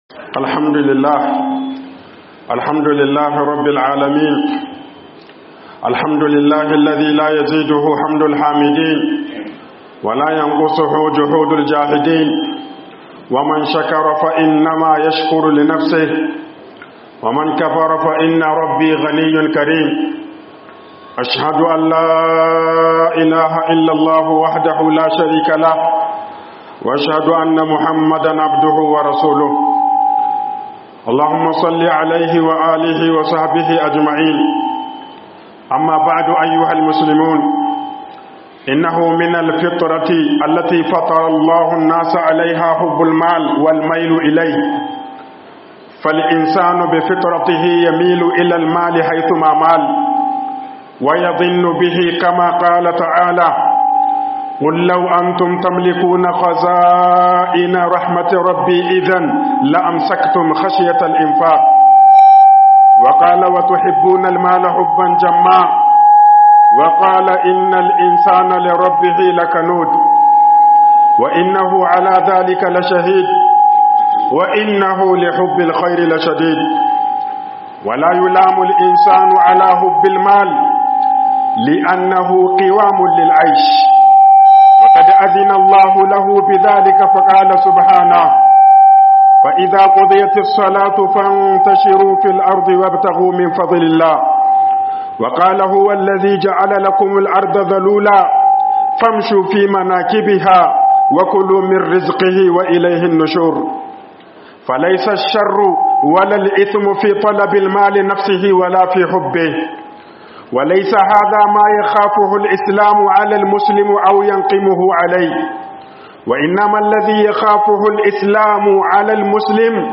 HATSARIN FIFITA DUKIYA AKAN KOMAI - HUƊUBOBIN JUMA'A